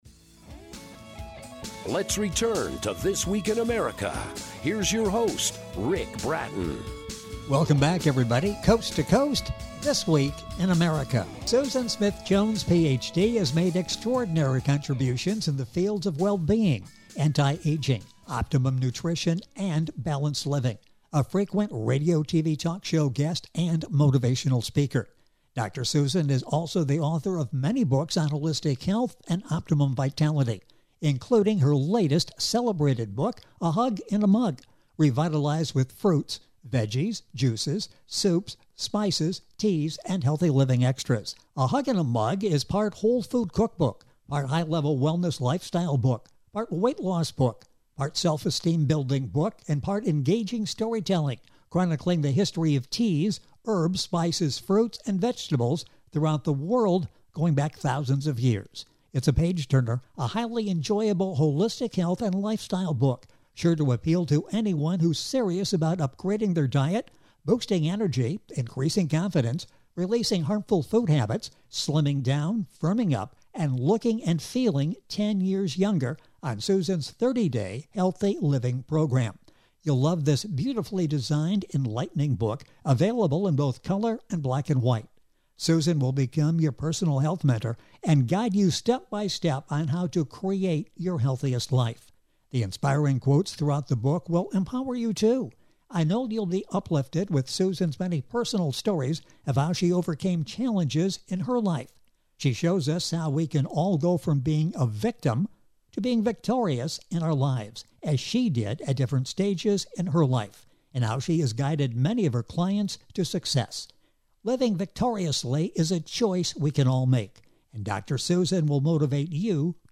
audio interview with tips from the book